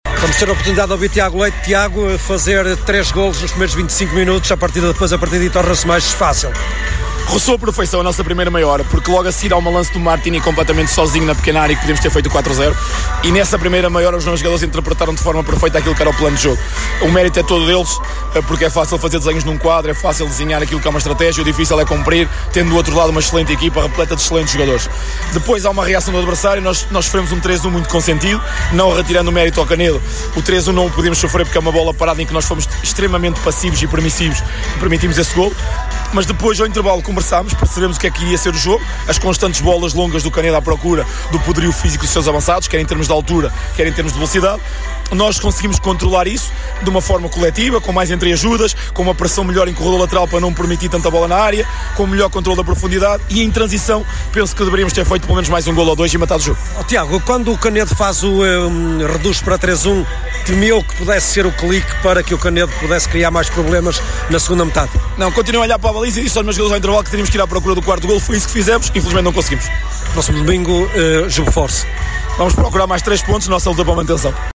acompanhou as emoções no campo das Valadas e ouviu os protagonistas no final.